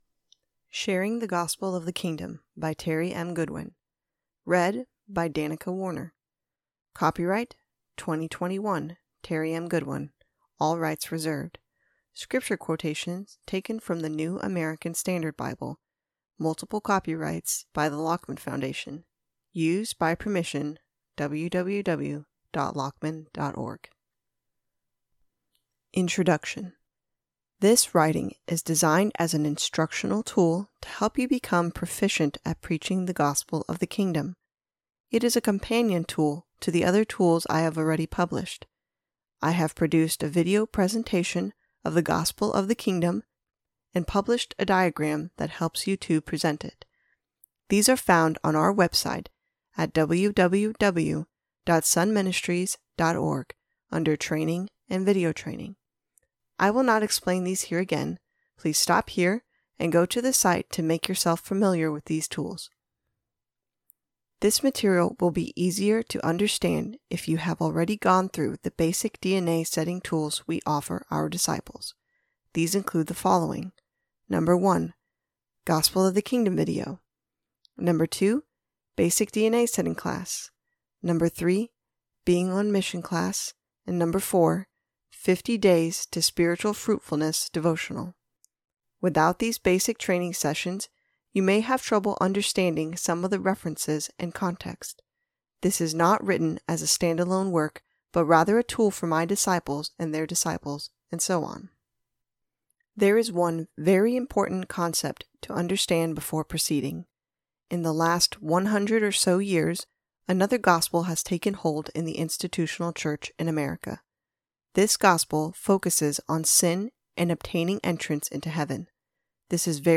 Audio books available for free download!